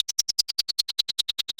RI_RhythNoise_150-03.wav